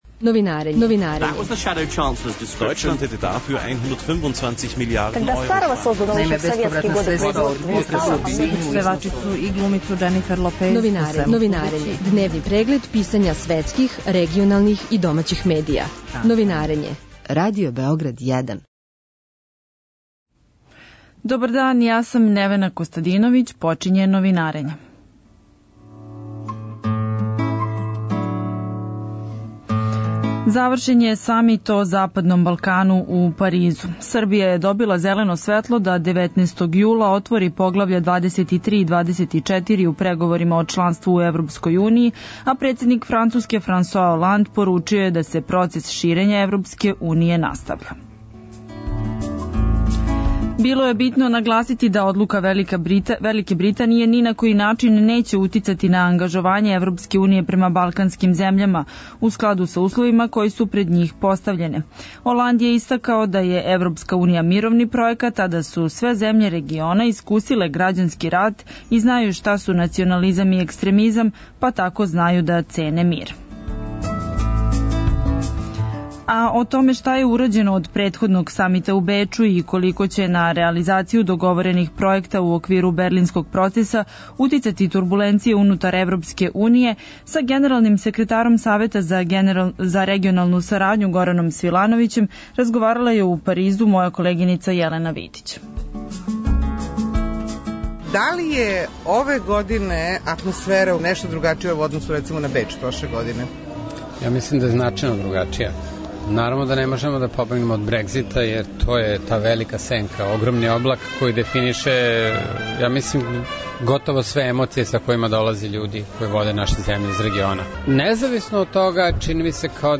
О томе шта је урађено од претходног самита у Бечу и колико ће на реализацију договорених пројеката у оквиру Берлинског процеса утицати турбуленције унутар Европске уније са генералним секретаром Савета за регионалну сарадњу Гораном Свилановићем разговарала је у Паризу